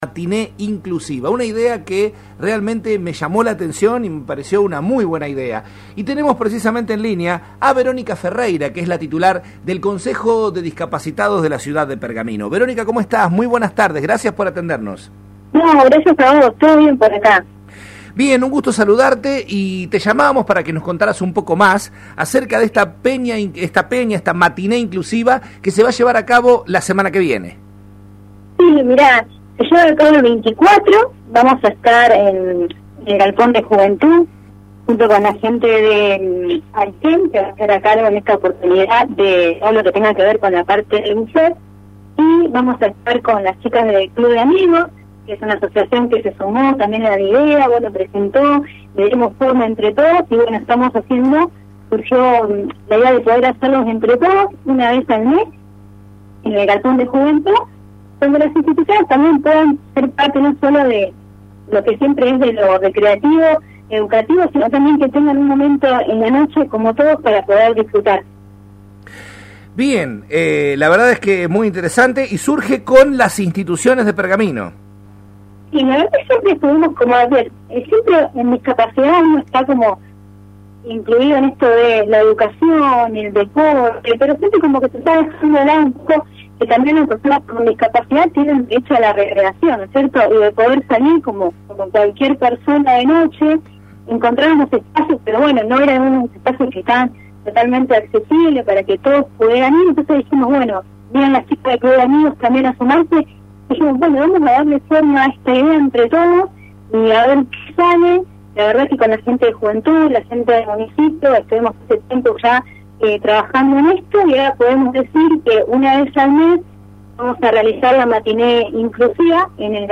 En el programa Nuestro Tiempo, que se emite por Radio Mon Pergamino AM 1540